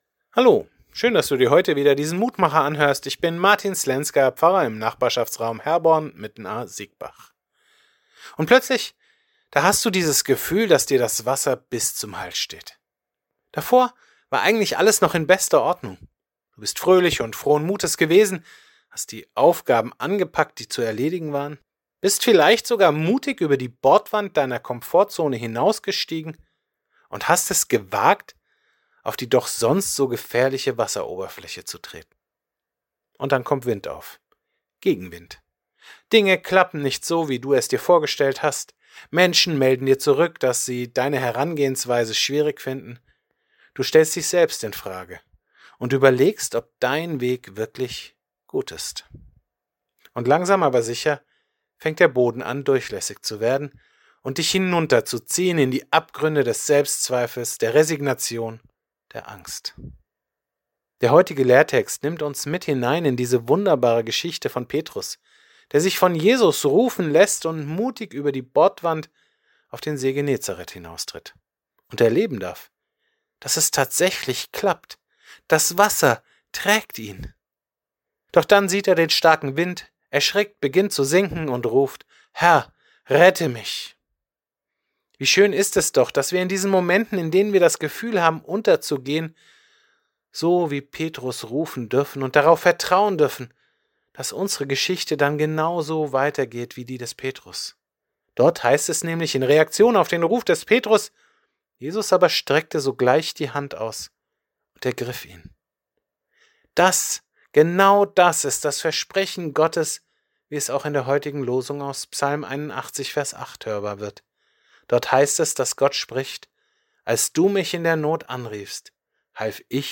Kurzer täglicher Andachtsimpuls zu Losung oder Lehrtext des Herrnhuter Losungskalender